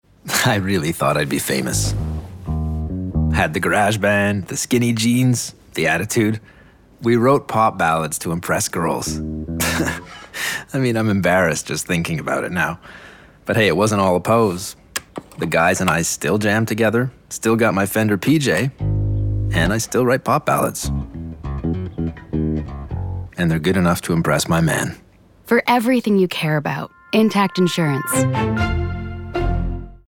Publicité (Intact Assurances) - ANG